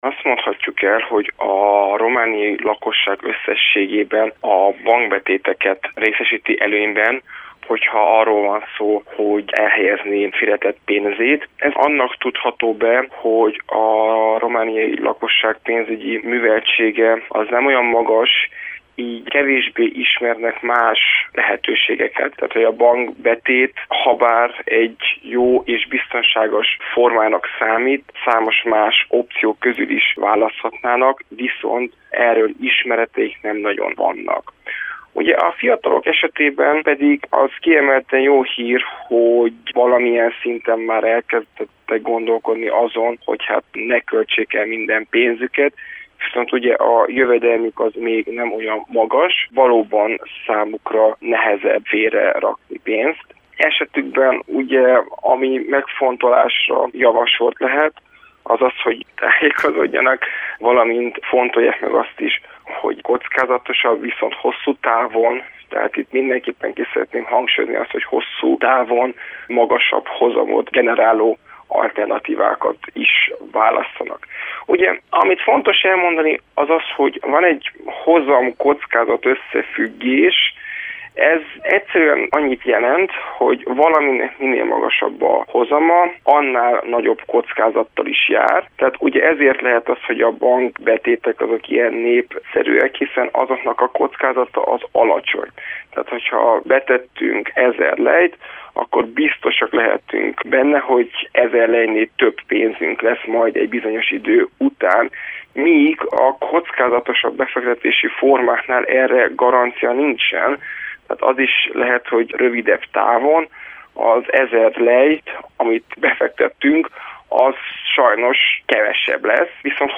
beszélgettünk arról, hogy hogyan spórol a lakosság.